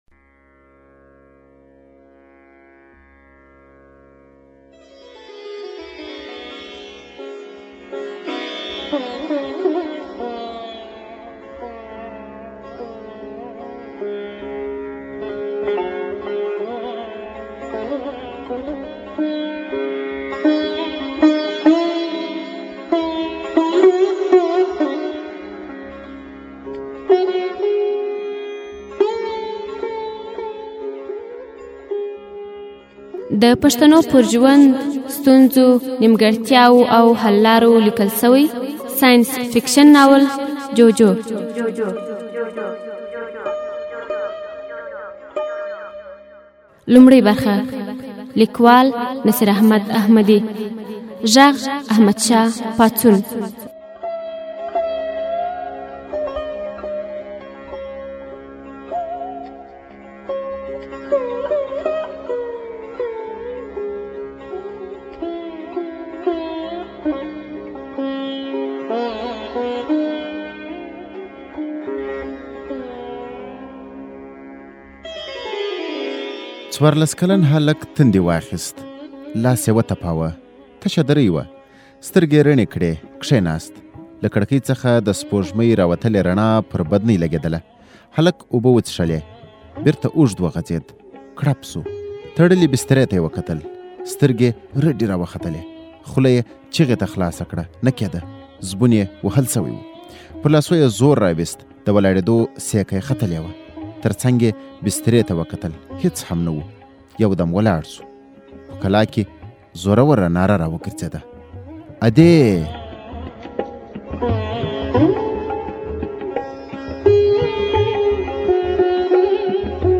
د پښتو ژبي نوموتی ناول (جُوجُو) په ږغ کي واوری. دا ناول، چي د پښتو پر ژوند، حالاتو او ناوړه دودونو لیکلی سوی، موضوعاتو په خورا ښه انداز او خوږه او هنري ژبه اشاره سوې.